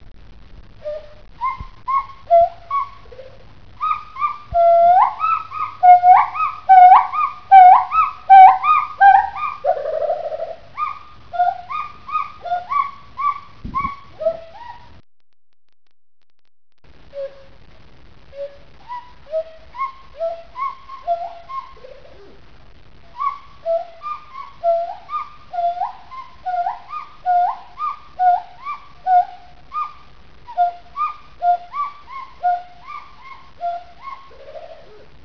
Hylobates pileatus
Short phrases of bi-phasic hoots ("oo-wa") of hiccup-like quality, simple hoots and short trills.
Female great call with an acceleration-type climax, like H. muelleri, with similar, fast bubbling note production, and without becoming slower at the end of the great call.
Male produces coda, beginning halfway through the great call.
Press to start sound Duet song, adult pair "Blacky" and "Iok", Zürich Zoo, Switzerland, 5 May 1988.